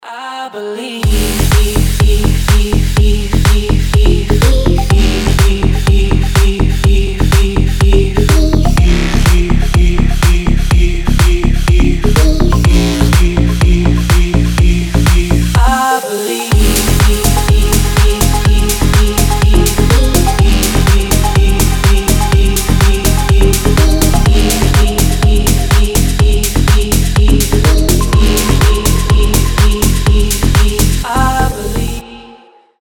громкие
deep house
мелодичные
EDM
басы
забавный голос